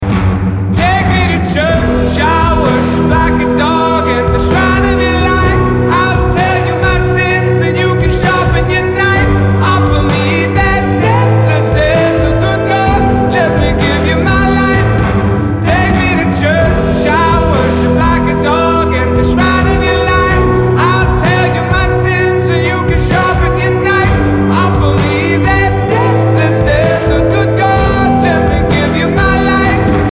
• Indie rock, soul